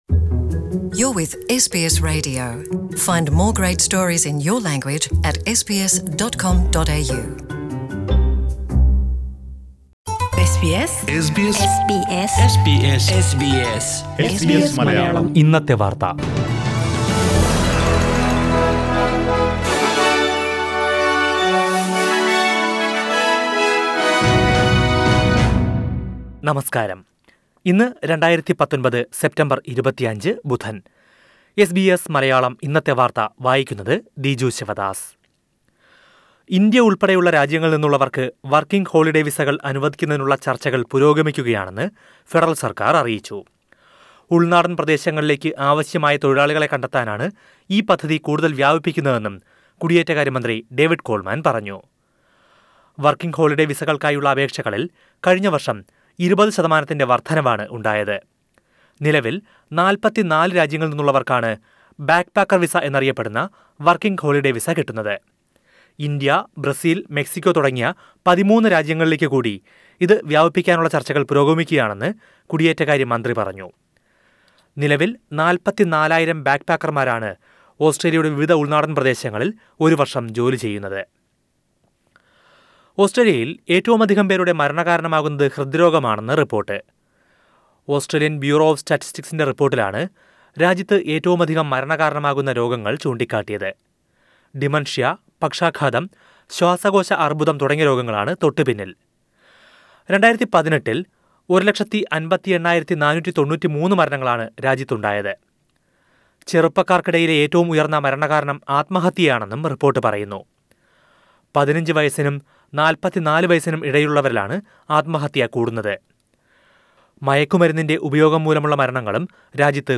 2019 സെപ്റ്റംബർ 25ലെ ഓസ്ട്രേലിയയിലെ ഏറ്റവും പ്രധാന വാർത്തകൾ കേൾക്കാം...